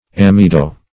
Search Result for " amido" : The Collaborative International Dictionary of English v.0.48: Amido \A*mi"do\, a. [From Amide .]